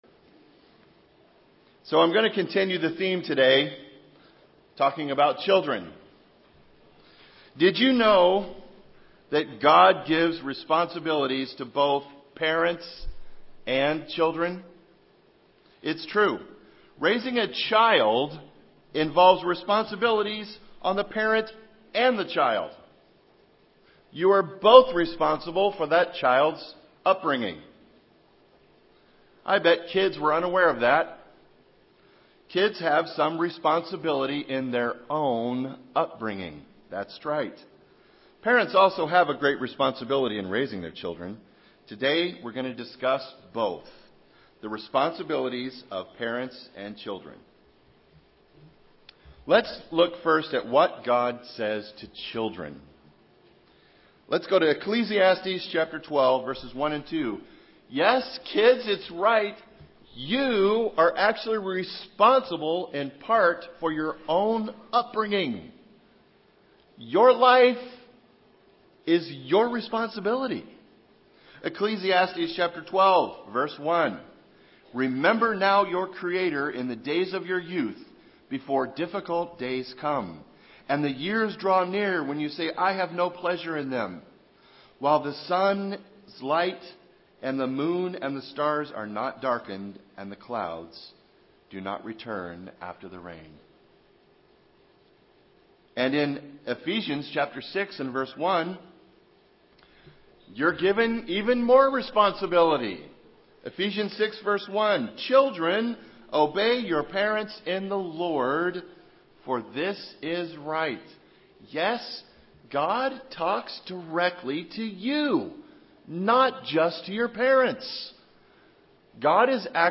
This sermon covers both the responsibility of the parents and the children in growing up.